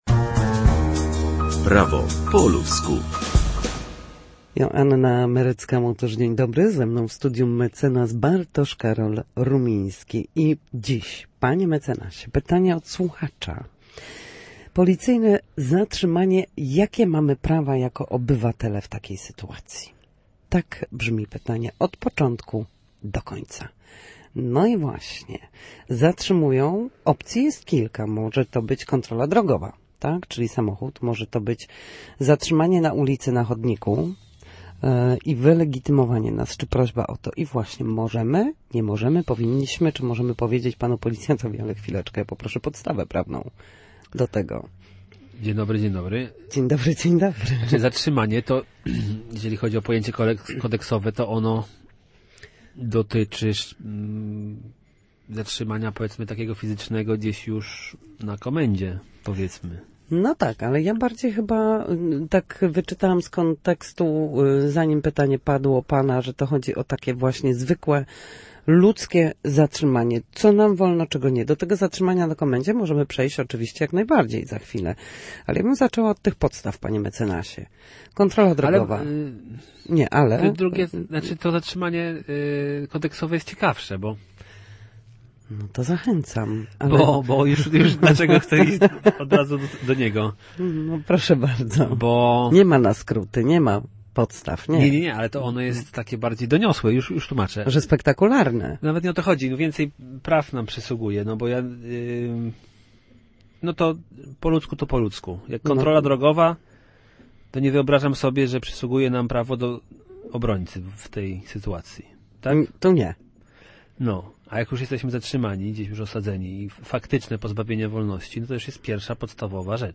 W każdy wtorek, o godzinie 13:40, na antenie Studia Słupsk przybliżamy meandry prawa.